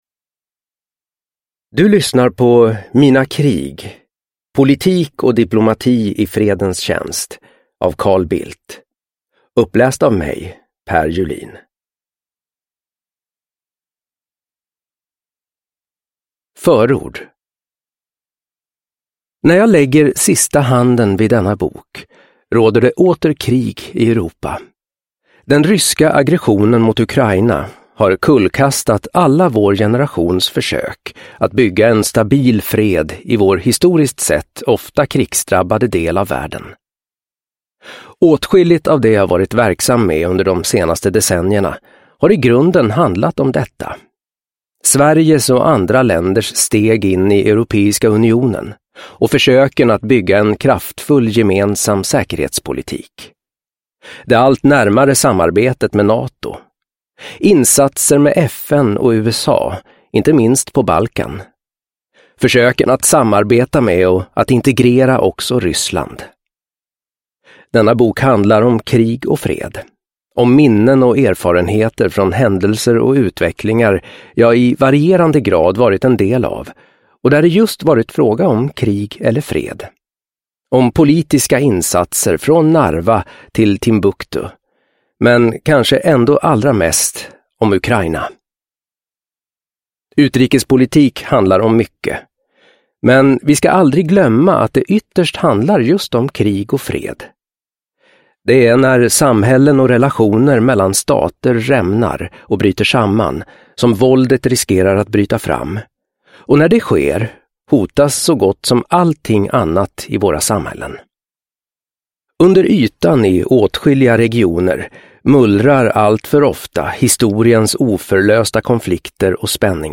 Mina krig : politik och diplomati i fredens tjänst – Ljudbok – Laddas ner